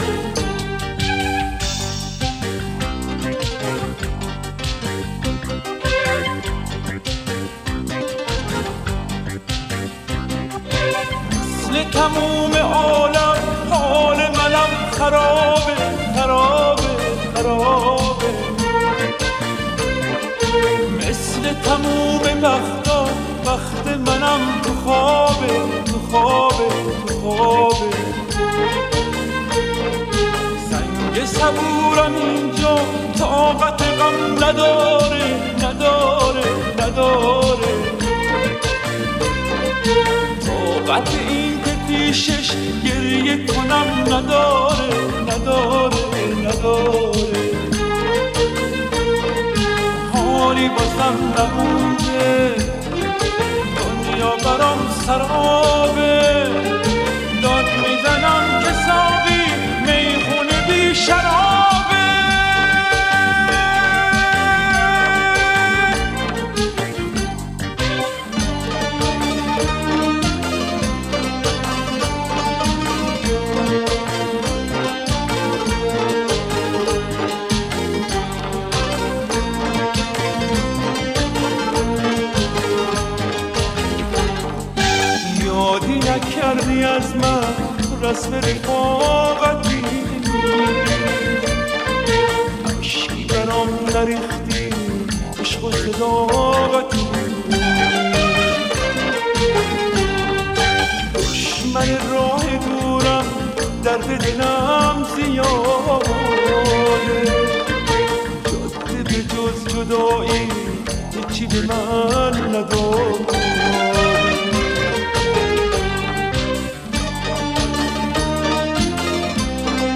با صدای مرد